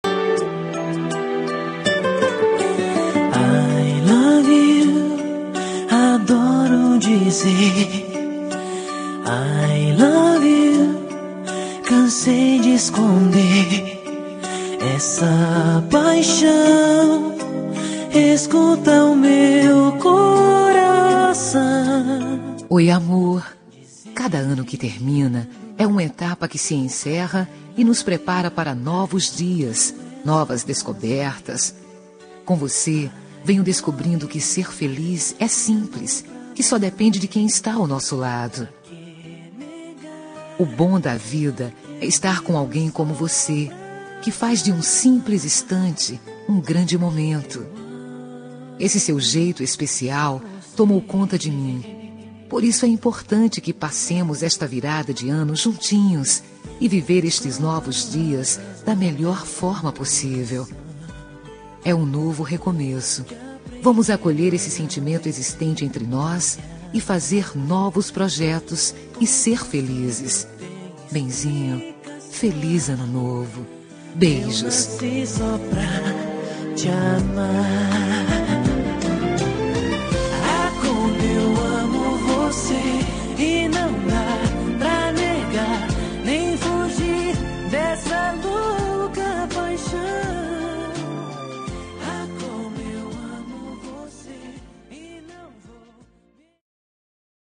Ano Novo – Romântica – Voz Feminina – Cód: 6423